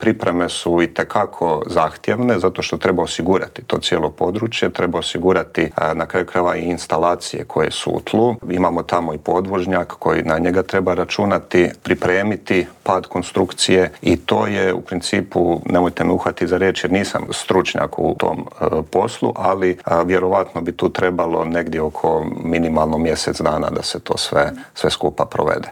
Intervjuu tjedna